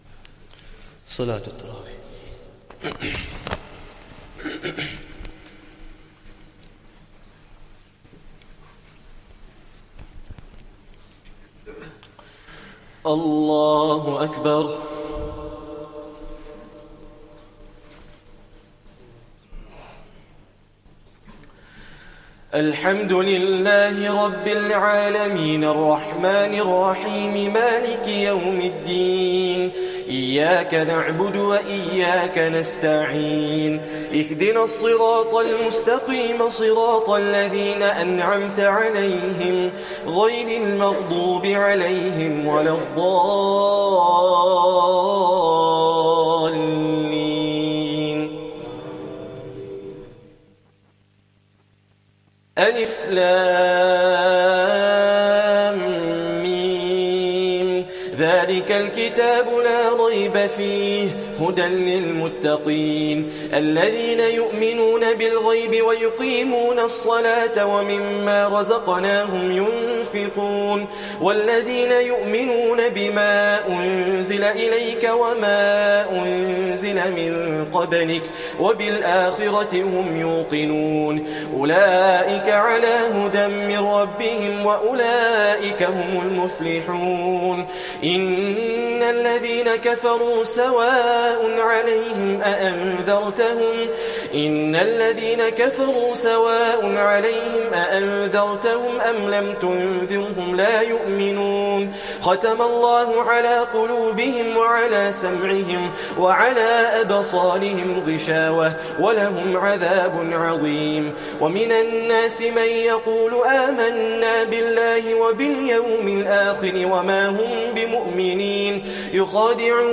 Taraweeh Prayer 1st Ramadhan